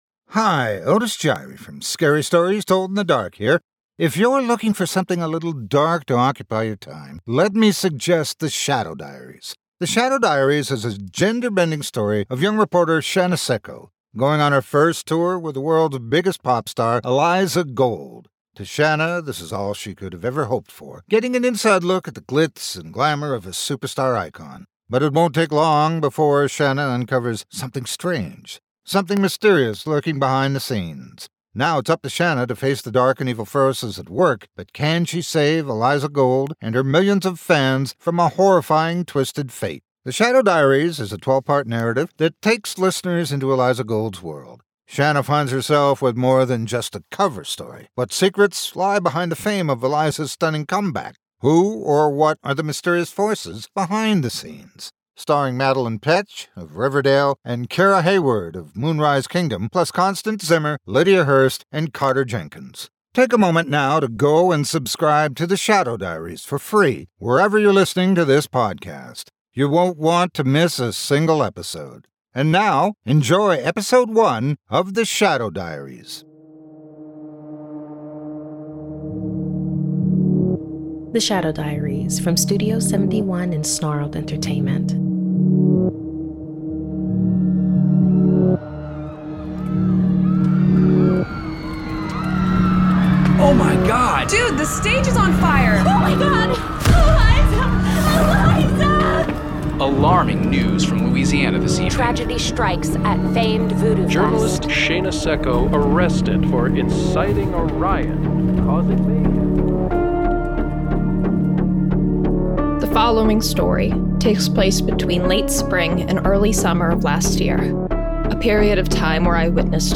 Introducing a brand-new horror narrative podcast, The Shadow Diaries.
Starring Madelaine Petsch (Riverdale) as pop star Eliza Gold, and Kara Hayward (Moonrise Kingdom) as hungry journalist Shana Secco, with Constance Zimmer, Lydia Hearst, and Carter Jenkins.